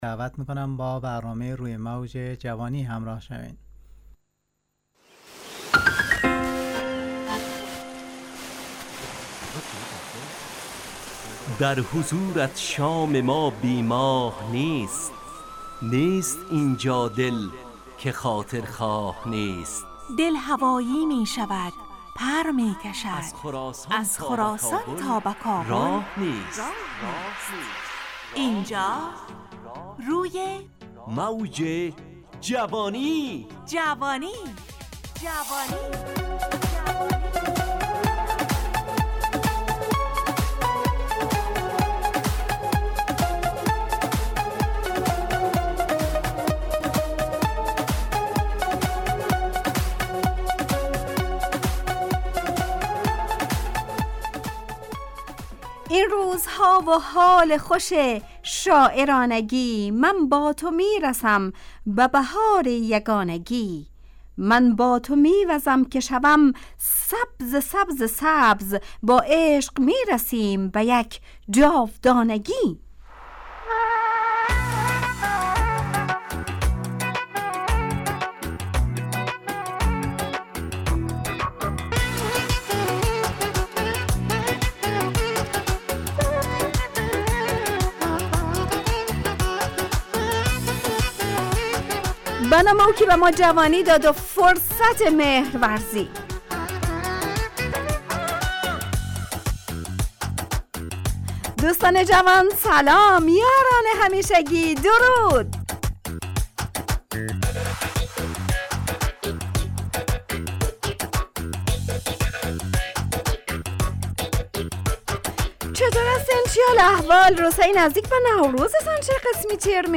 روی موج جوانی، برنامه شادو عصرانه رادیودری.
همراه با ترانه و موسیقی مدت برنامه 55 دقیقه .